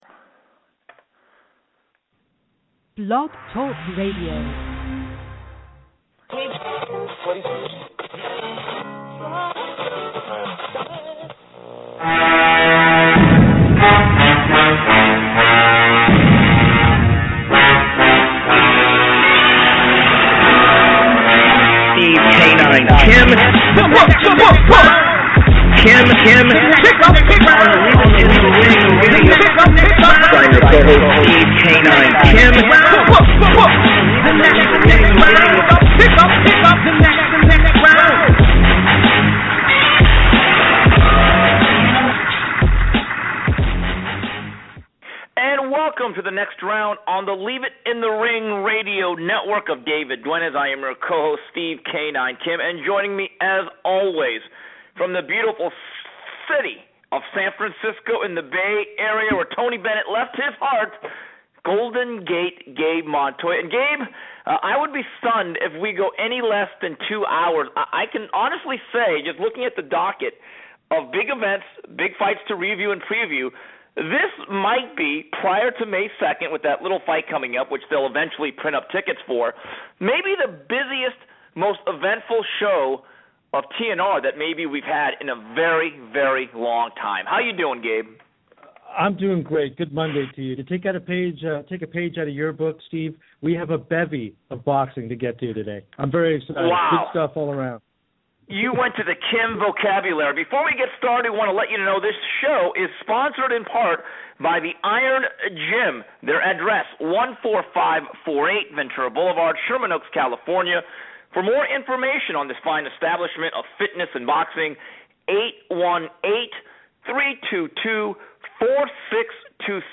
TNR will also discuss Julio Cesar Chavez, Jr's return to the ring against Andrzej Fonfara this Saturday, live on Showtime. And as always, news, notes and your calls.